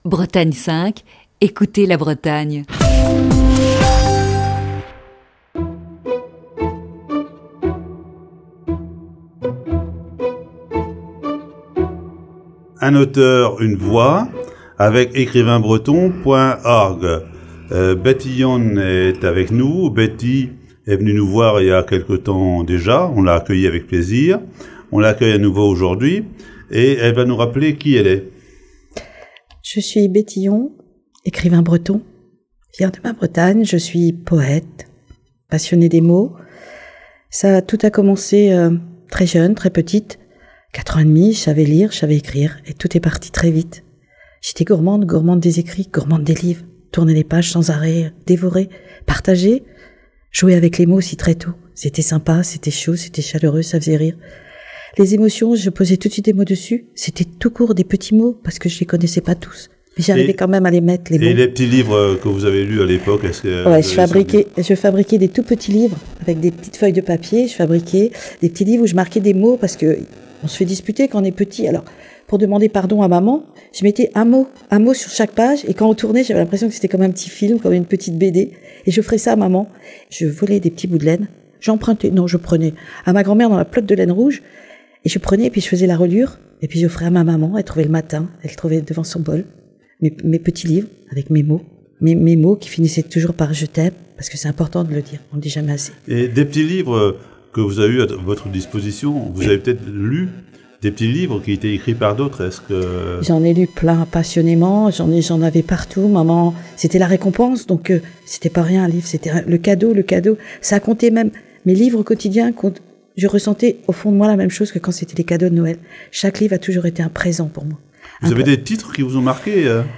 Chronique du 17 février 2020.